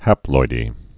(hăploidē)